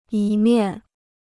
一面 (yī miàn): one side; one aspect.
一面.mp3